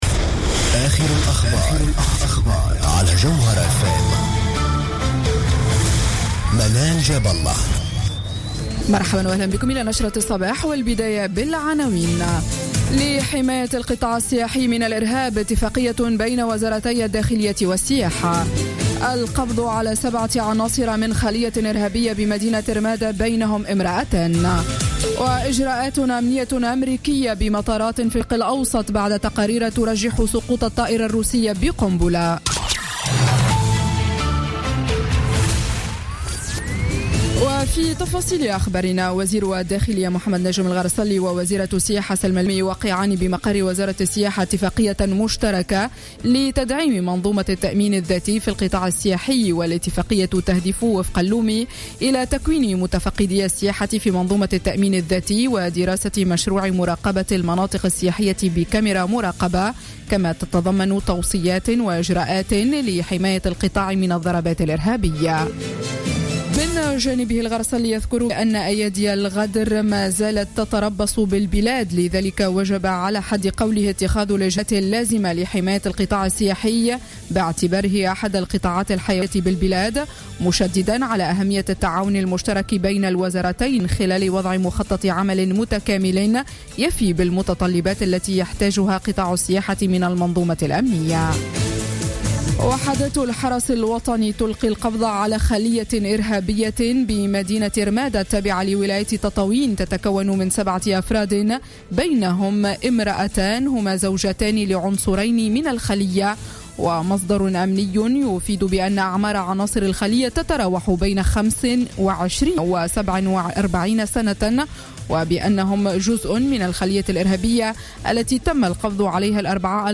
نشرة أخبار السابعة صباحا ليوم السبت 7 نوفمبر 2015